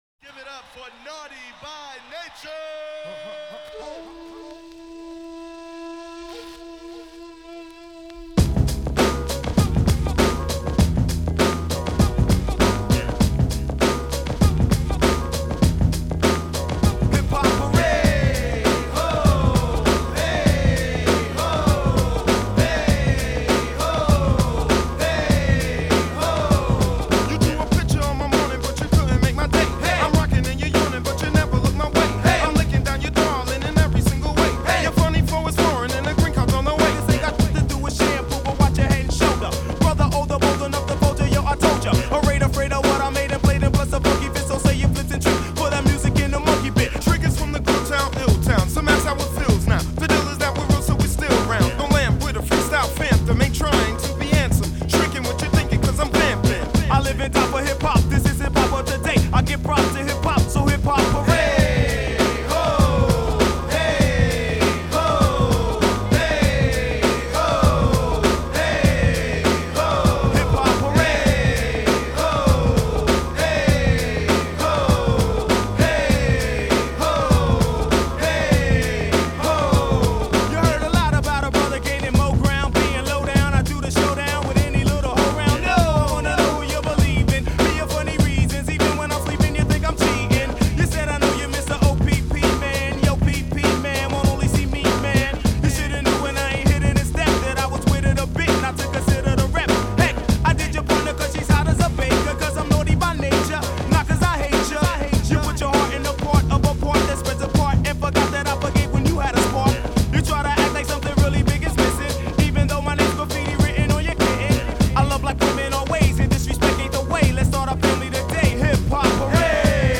hip hop rap هیپ هاپ رپ الد اسکول old school